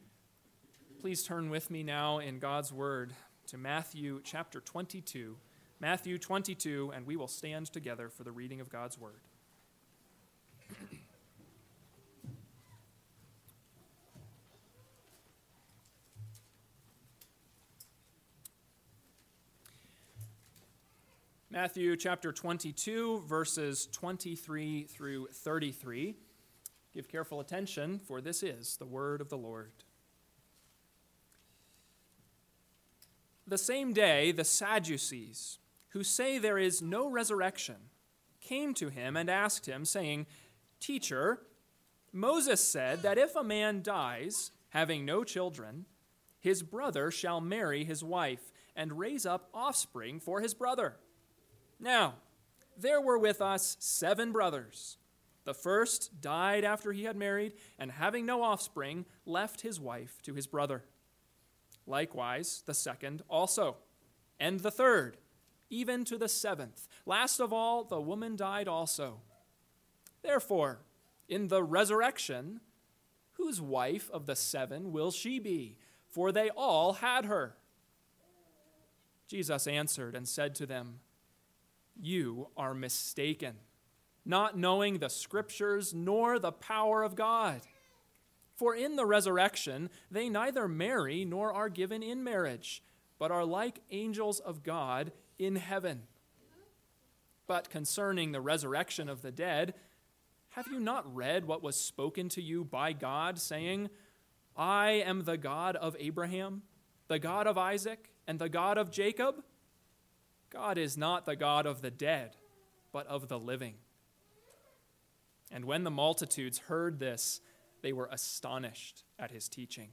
AM Sermon – 10/20/2024 – Matthew 22:23-33 – Northwoods Sermons